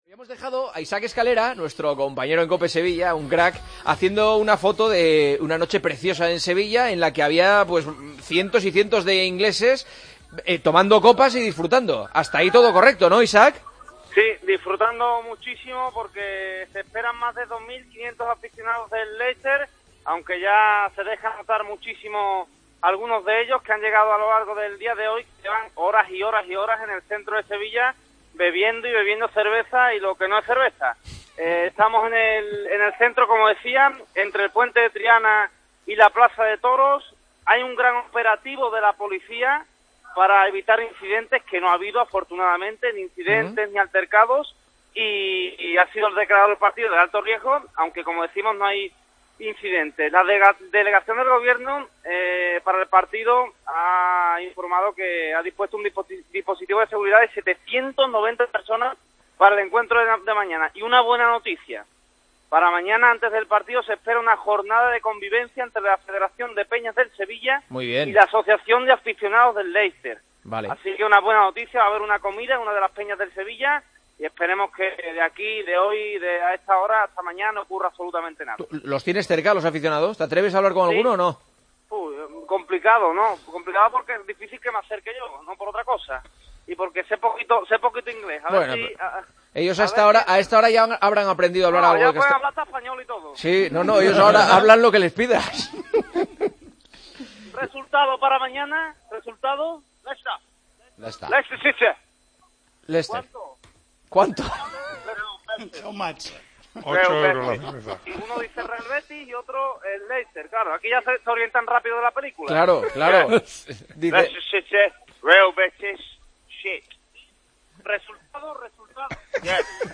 La porra de los aficionados del Leicester a pie de bar, en El Partidazo de COPE
conversó con varios aficionados del conjunto inglés en la previa del partido de Liga de Campeones ante el Sevilla.